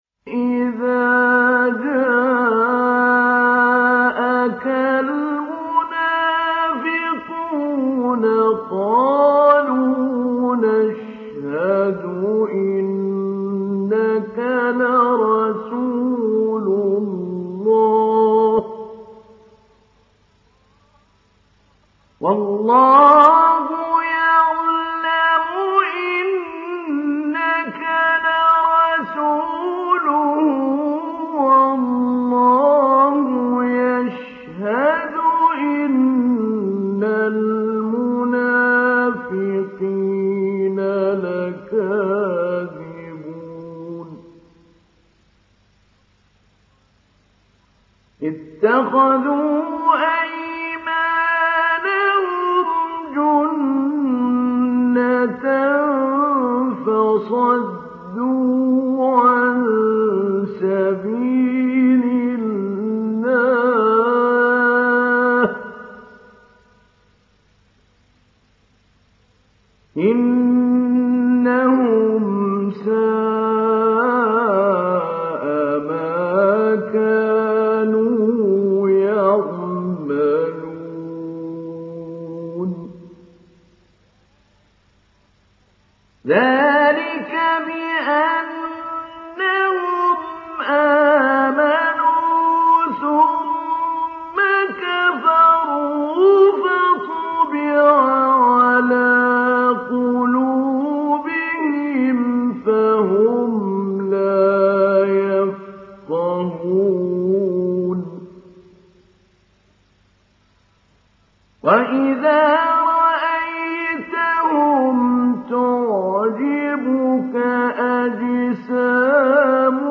Münafikun Suresi İndir mp3 Mahmoud Ali Albanna Mujawwad Riwayat Hafs an Asim, Kurani indirin ve mp3 tam doğrudan bağlantılar dinle
İndir Münafikun Suresi Mahmoud Ali Albanna Mujawwad